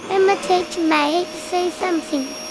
role1_VacuumCleaner_1.wav